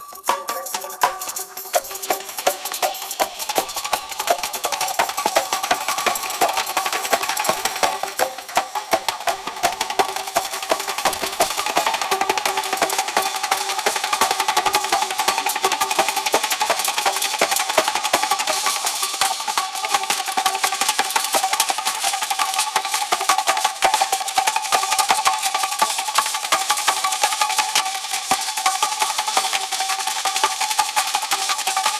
If someone wants to try generative AI music/looper maker I have a Colab that does that.